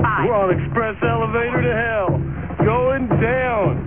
Played By-Bill Paxton